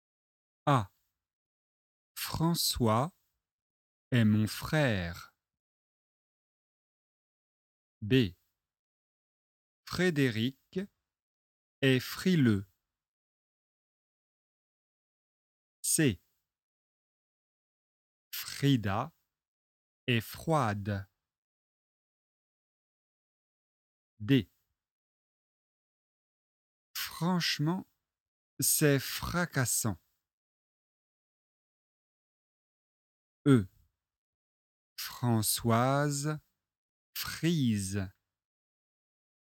F consonne labio dentale - exercices phonétiques
[f] est une consonne labio-dentale (labio = lèvre / dentale = dent) .
!! Le son est chuintant.
[f] =  consonne non voisée
[f] =  consonne fricative
phonetique-f.mp3